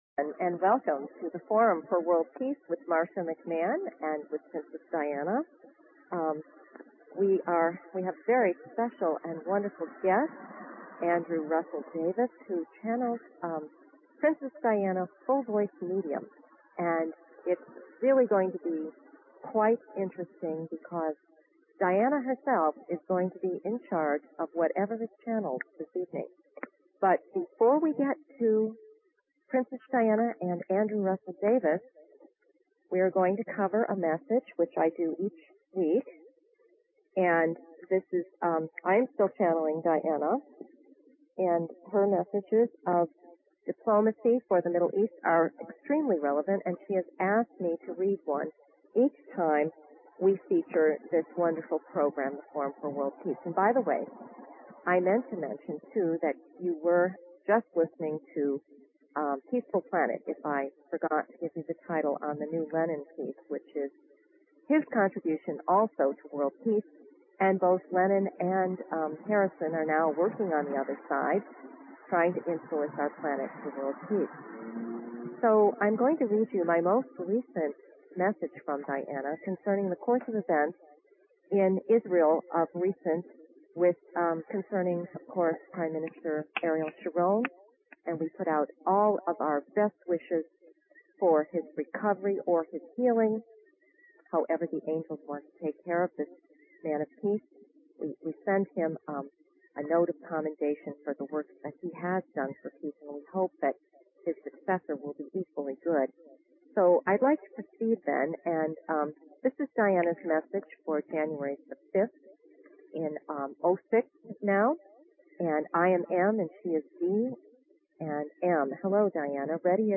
Talk Show Episode, Audio Podcast, Forum For World Peace and Courtesy of BBS Radio on , show guests , about , categorized as